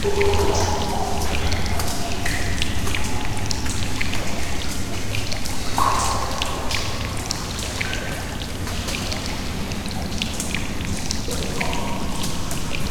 cave.ogg